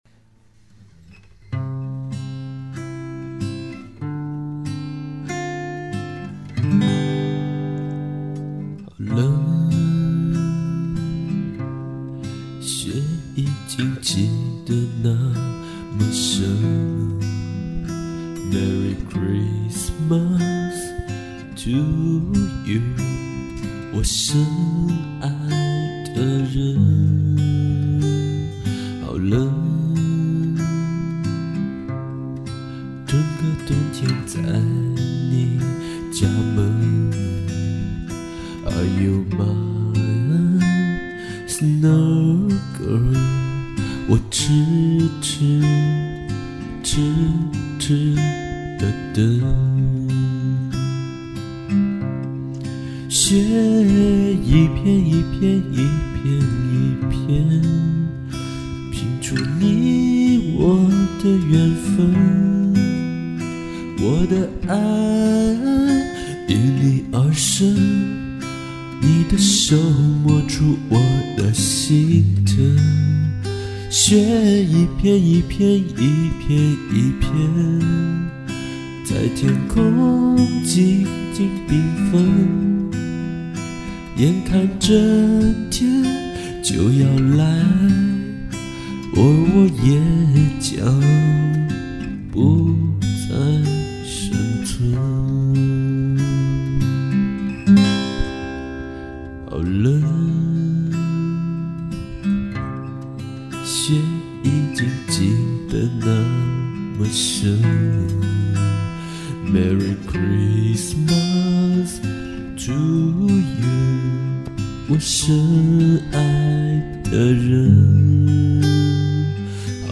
昨天下午，在温暖的阁楼小窝里，弹着吉它，看窗外飘落凡间的精灵，全然忘记了寒冷。
即兴演奏的，错误很多，麦克风有点受潮，忽略暴音。